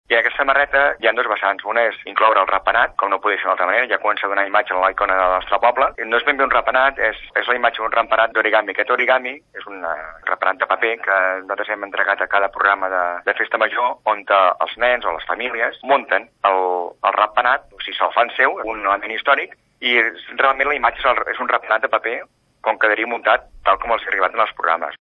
El regidor de festes, Albert Cuní, ha explicat que el ratpenat és la imatge central de Sant Roc.